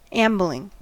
Uttal
Uttal US Ordet hittades på dessa språk: engelska Ingen översättning hittades i den valda målspråket.